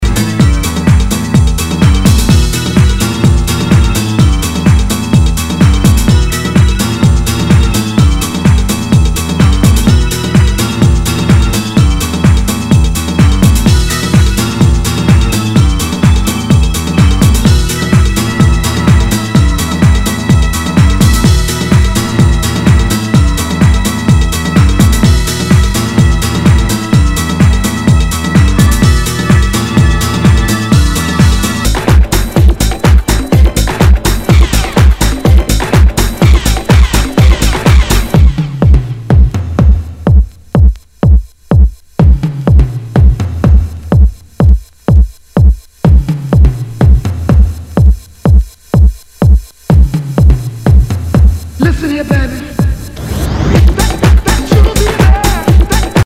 HOUSE/TECHNO/ELECTRO
ユーロ・ハウス・クラシック！